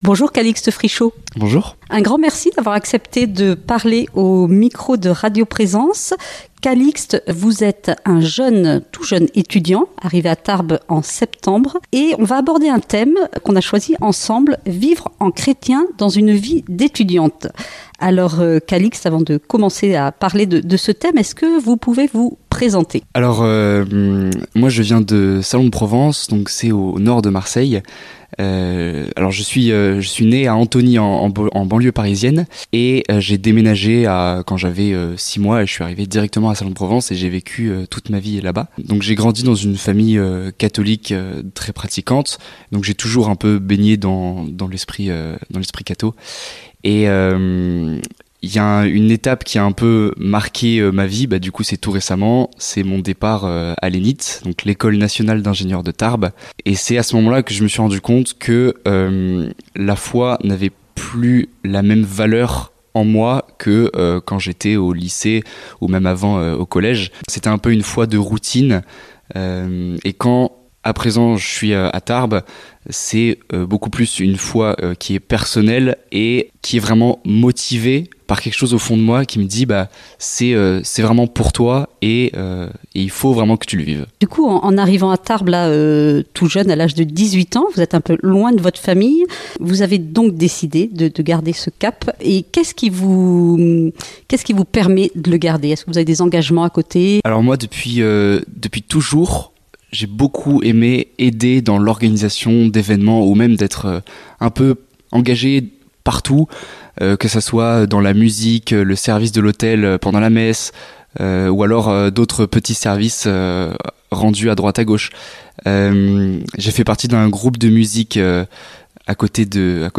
Un très beau t témoignage plein de Joie et d’Espérance !